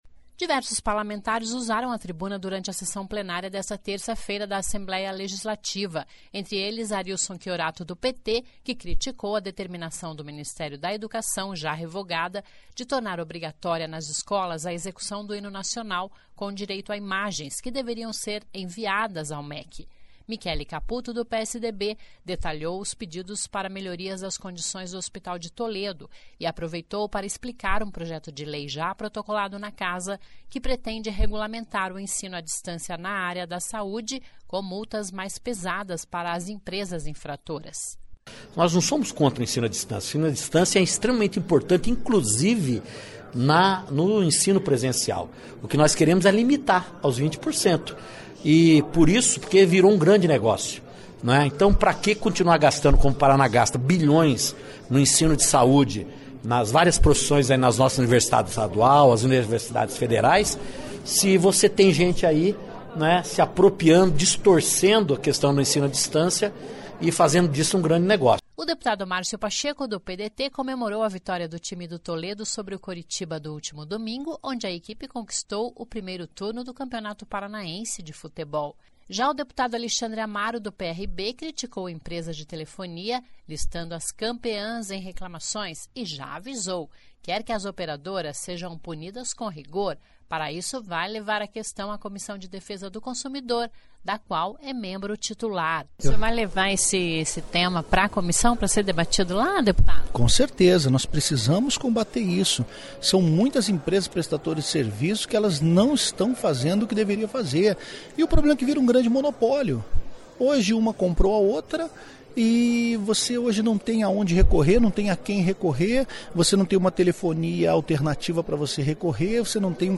Diversos parlamentares usaram a Tribuna  durante a sessão Plenária desta terça-feira (26).